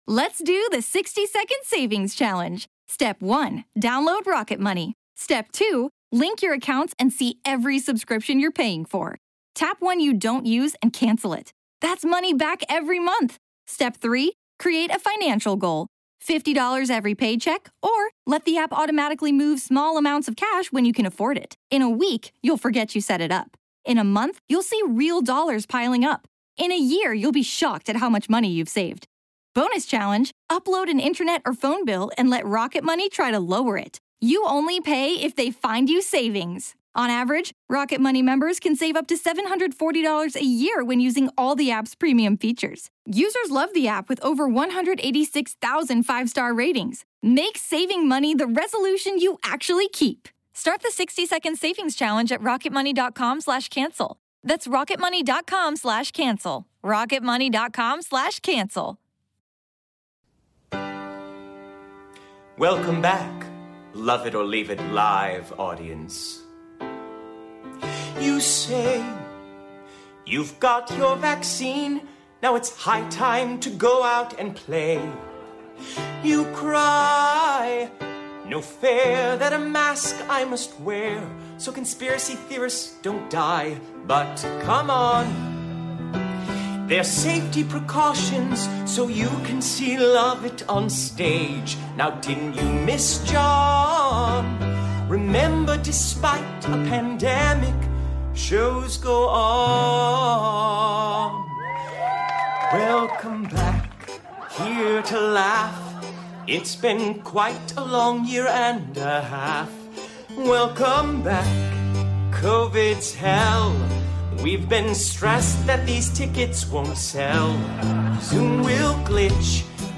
We did it live!